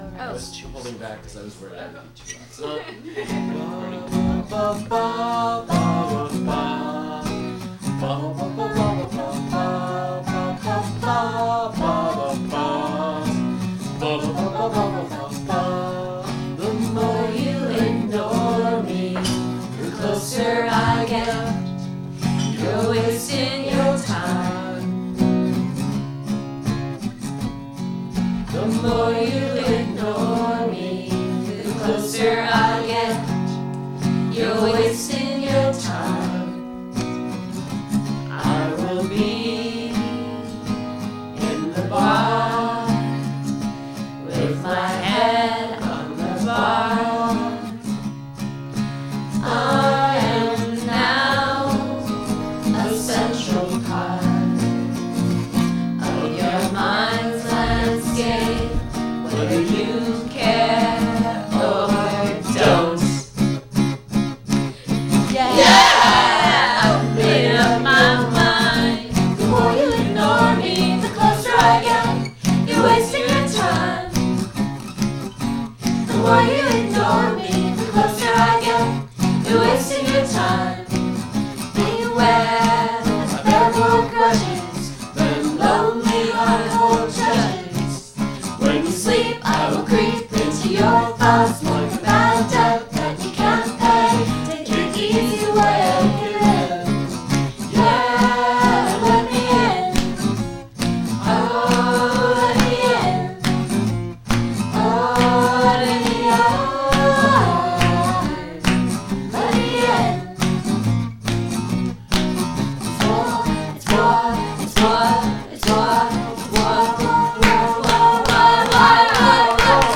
singing, snapping, and clapping